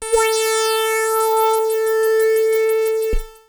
/audio/sounds/Extra Packs/musicradar-synth-samples/Roland S100/Roland F Multi/
Roland F A4.wav